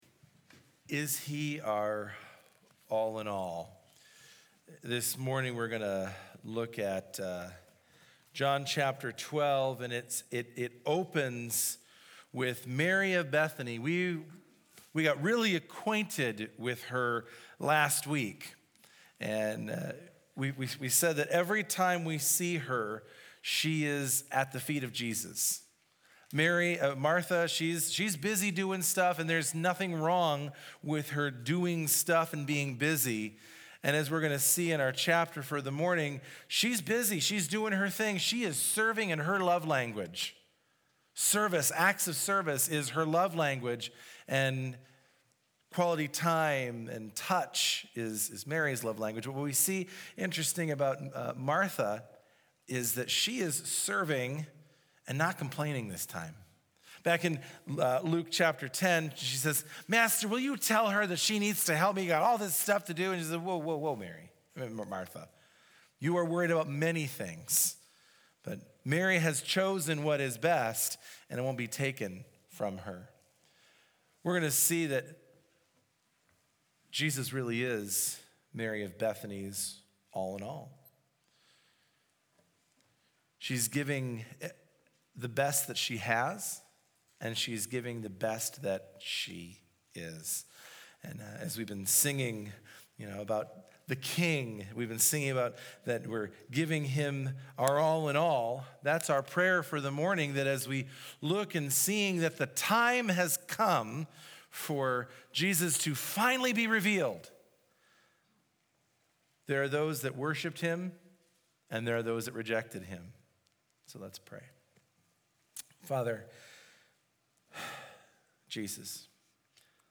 This Weeks Sermon